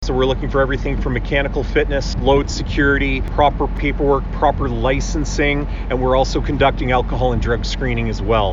Oct-6-CMV-Press-Conference-1.mp3